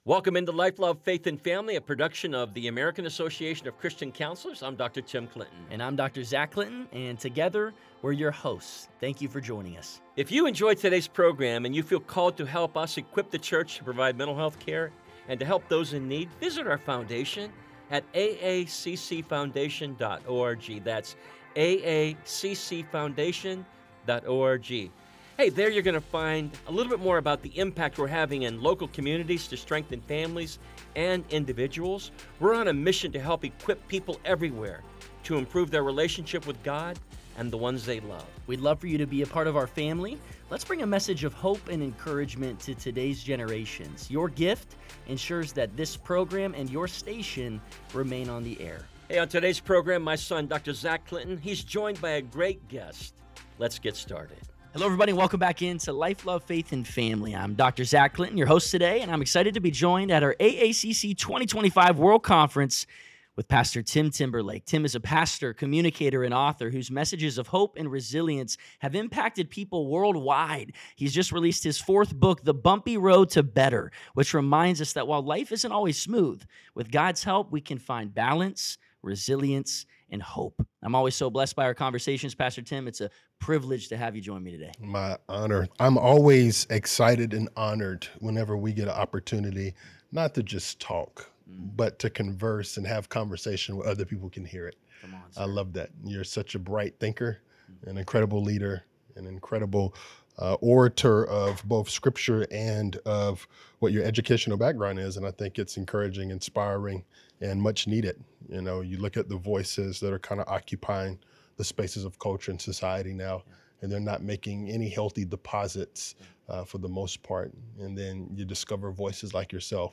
a hope-filled conversation